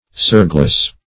Surgeless \Surge"less\, a. Free from surges; smooth; calm.